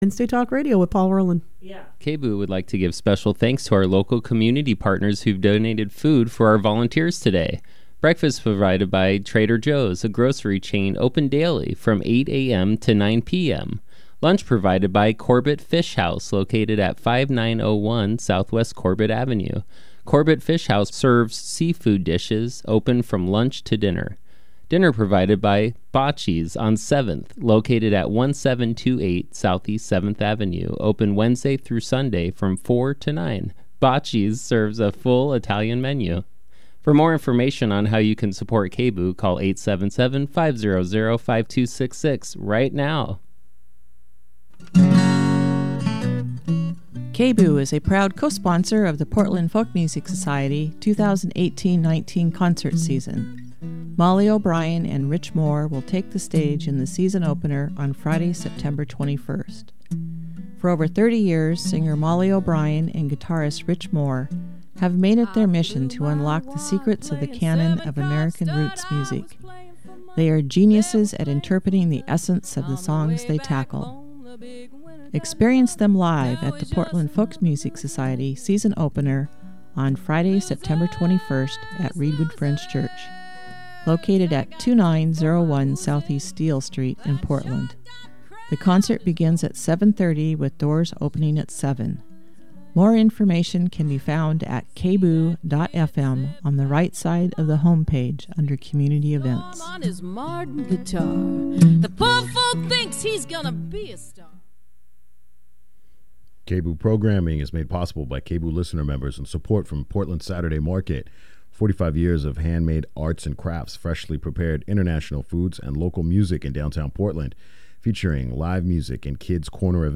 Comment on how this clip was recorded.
Today's show is pre-recorded, so no call-ins!